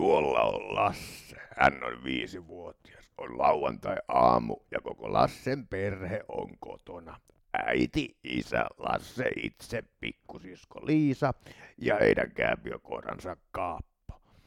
Kuuntele ääninäyte spasmodisesta dysfoniasta.